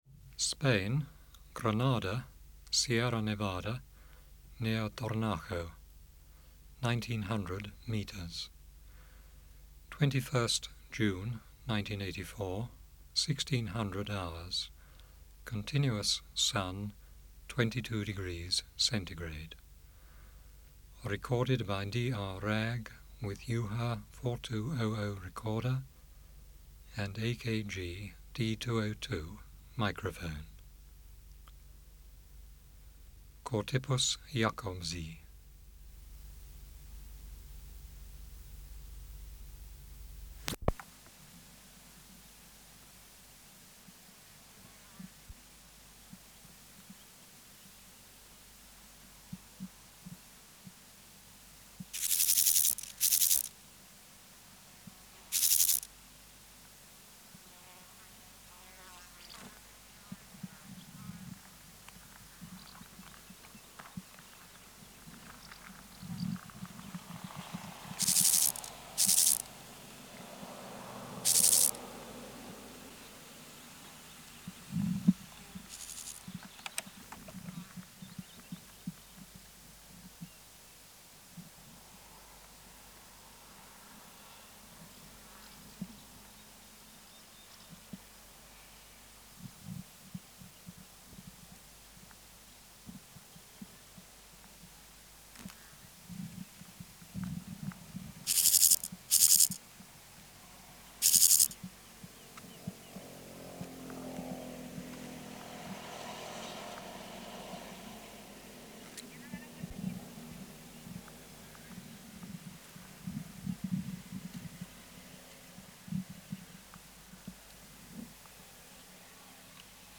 Project: Natural History Museum Sound Archive Species: Chorthippus (Glyptobothrus) jacobsi